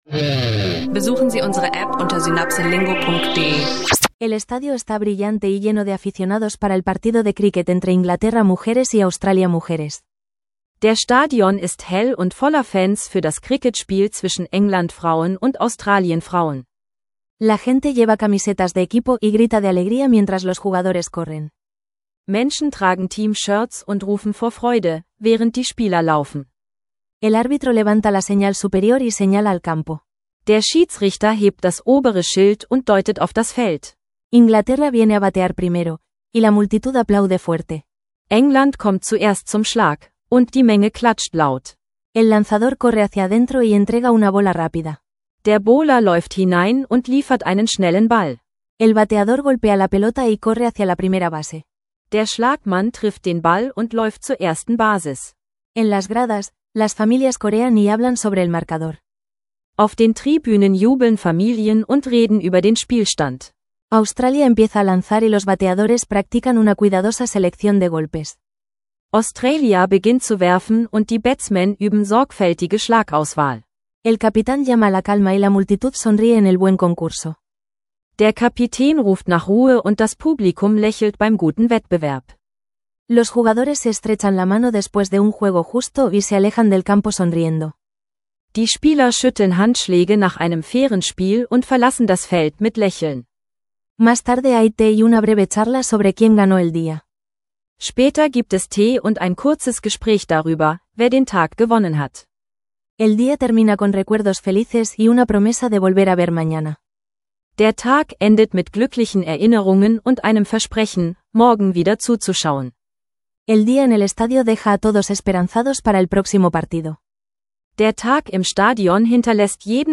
Zwei kurze Geschichten: Sportvokabular beim Cricket und praxisnahe Karriereentscheidungen – lernen Sie Spanisch im Alltag mit leicht verständlichen Dialogen.